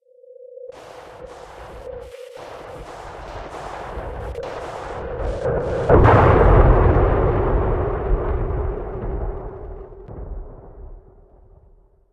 emi_blowout_01.ogg